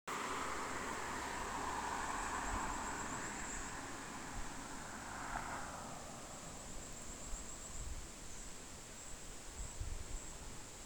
Saíra de Antifaz (Pipraeidea melanonota)
Nombre en inglés: Fawn-breasted Tanager
Provincia / Departamento: Tucumán
Condición: Silvestre
Certeza: Vocalización Grabada